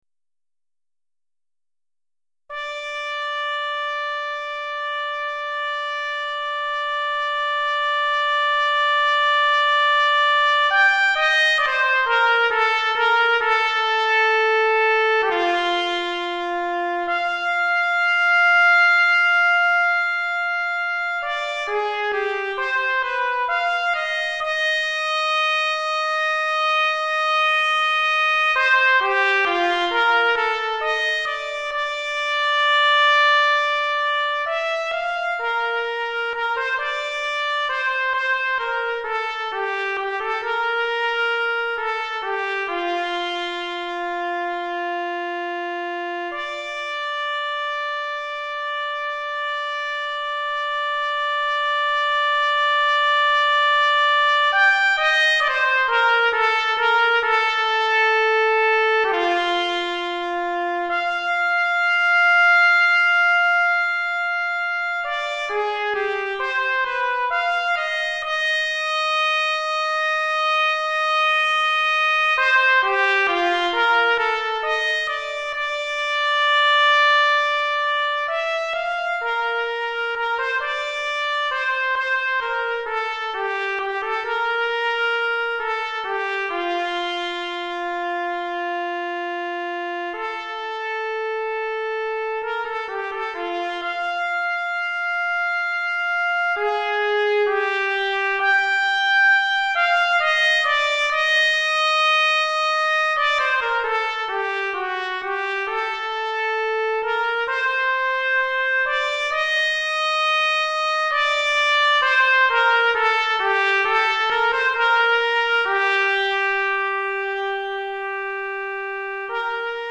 Trompette Solo